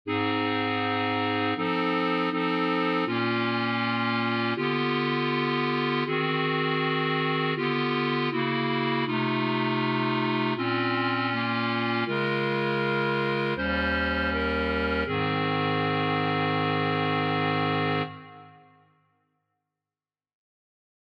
Key written in: A♭ Major
How many parts: 4
Type: Barbershop
All Parts mix: